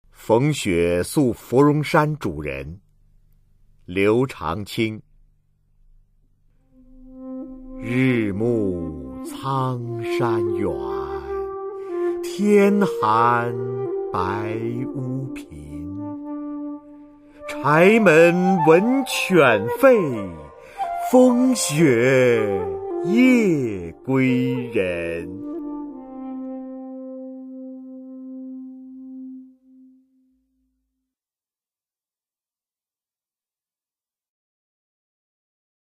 [隋唐诗词诵读]刘长卿-逢雪宿芙蓉山主人（男） 配乐诗朗诵